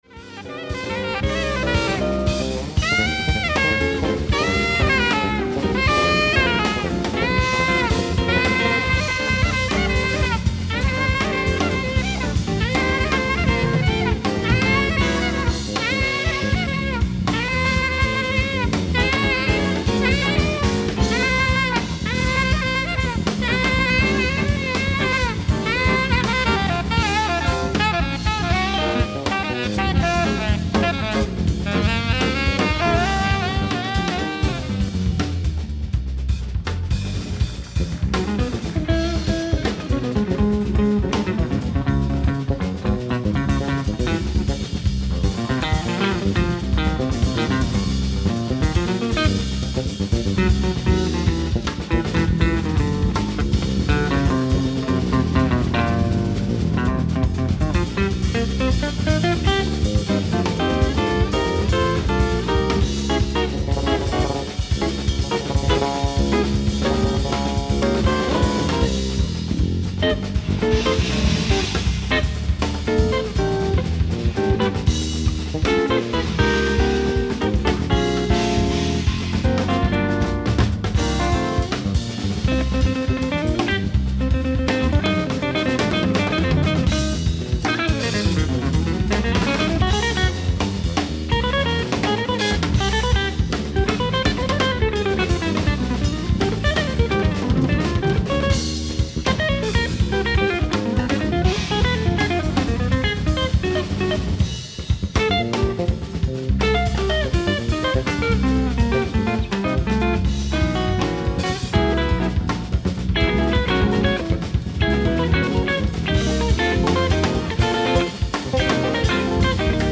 ライブ・アット・ストックホルム、スウェーデン
※試聴用に実際より音質を落としています。